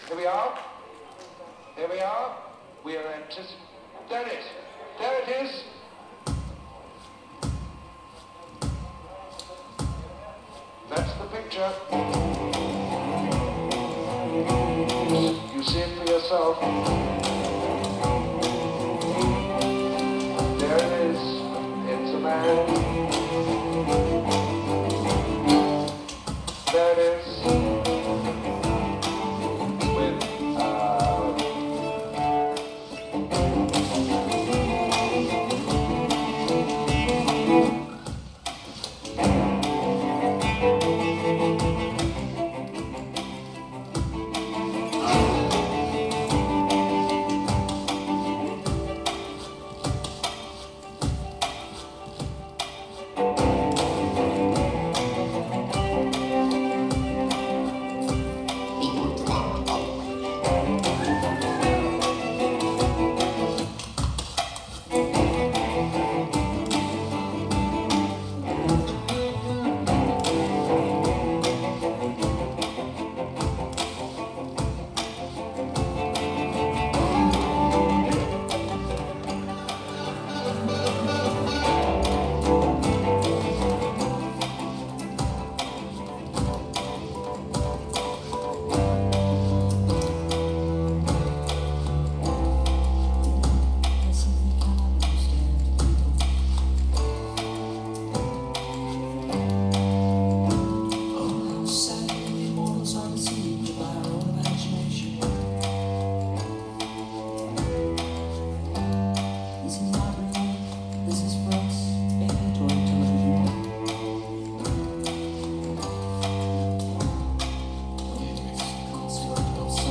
2006-04-21 UW HUB – Seattle, WA